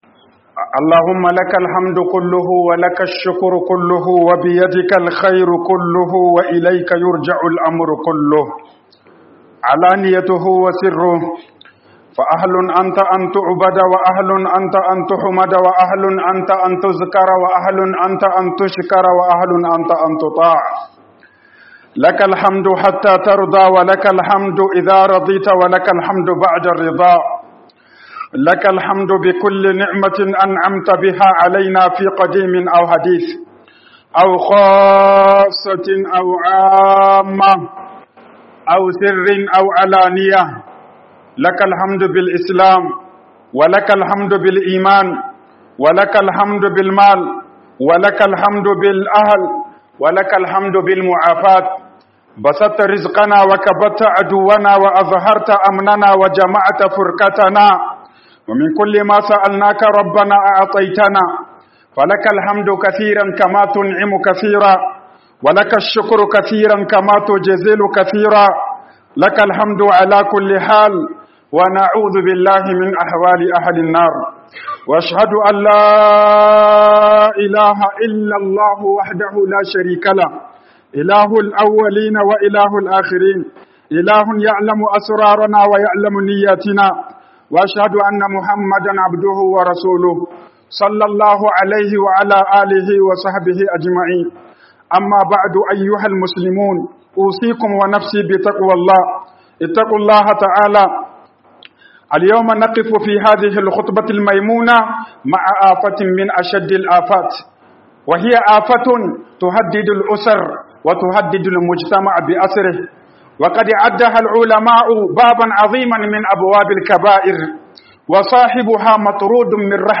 ZAMANTAKEWAR AURE - HUƊUBOBIN JUMA'A